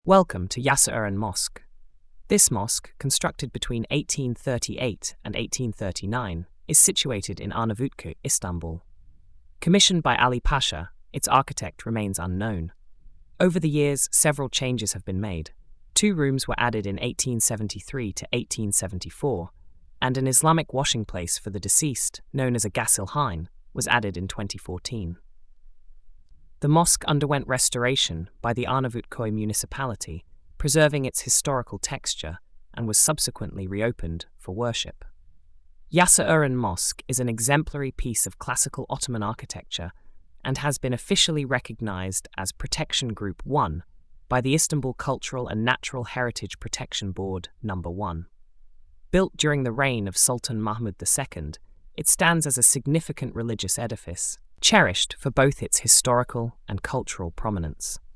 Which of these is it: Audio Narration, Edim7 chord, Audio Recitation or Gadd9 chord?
Audio Narration